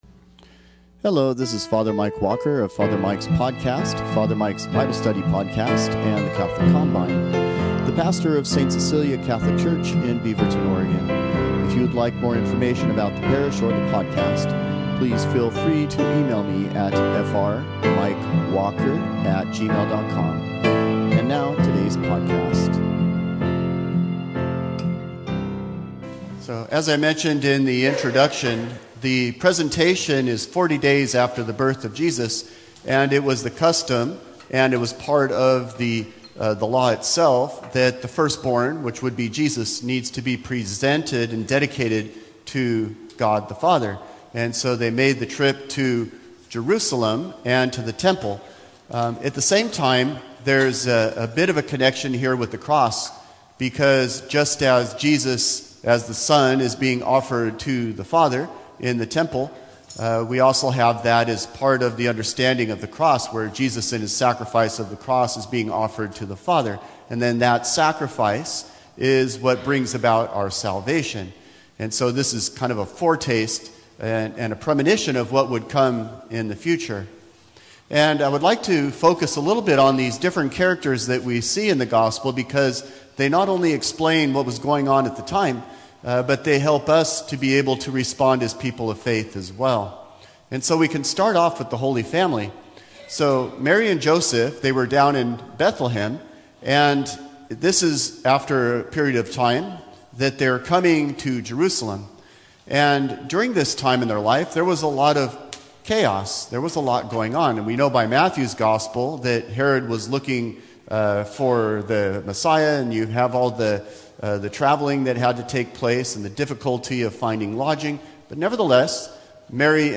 Homilies # Sermons